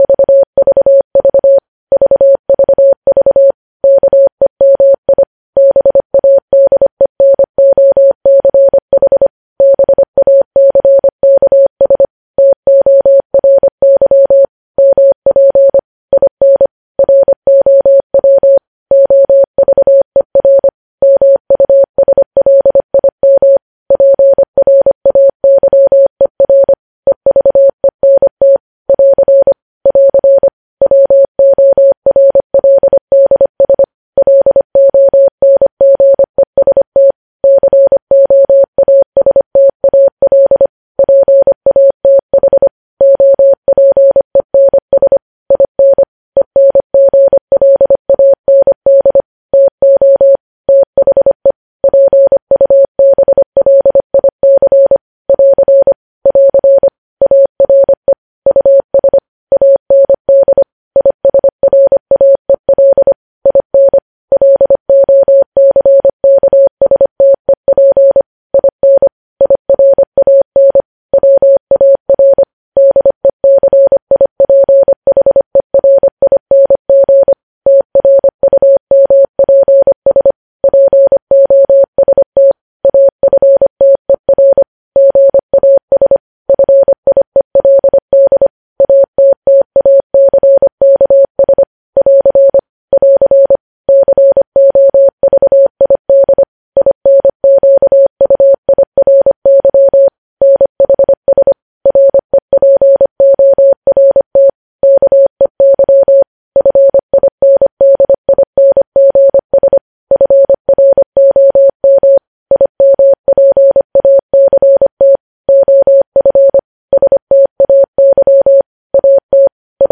News Headlines in Morse Code at 25 WPM
News Headlines in Morse code, updated daily